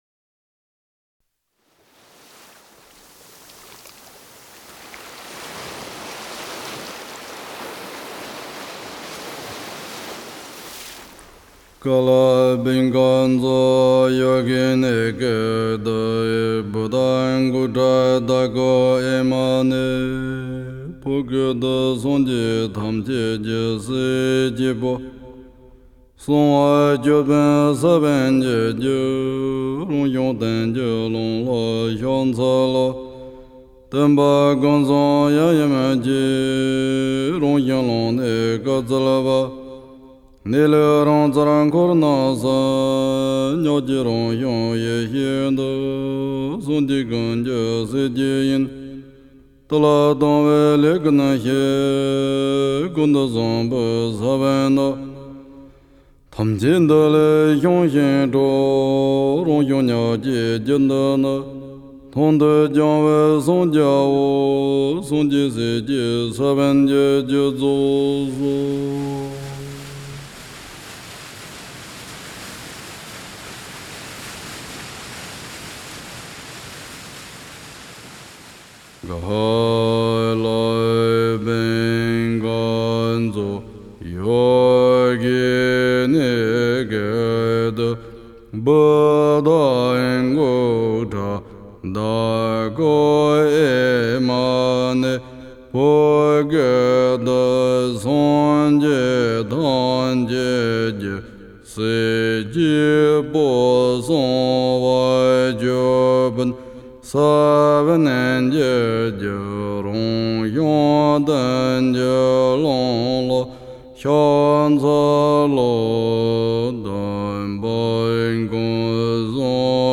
Тибетская музыка